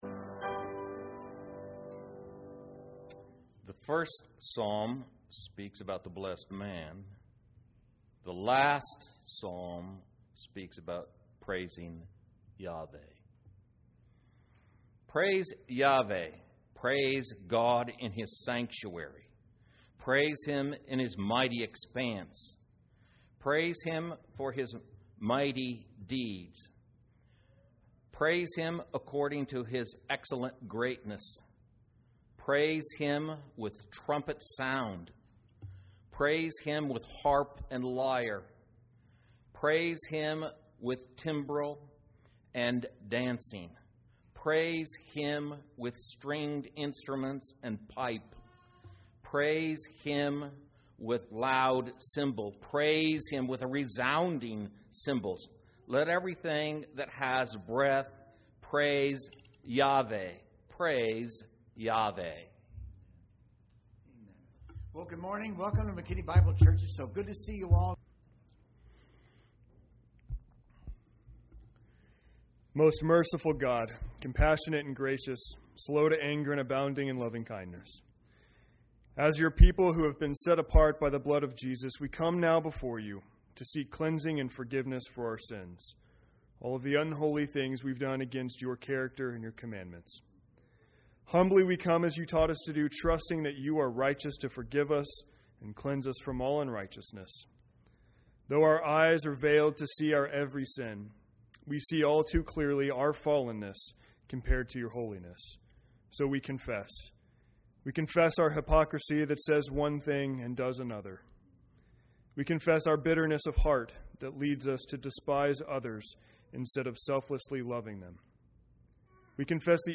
A message from the series "Kingdom Citizenship."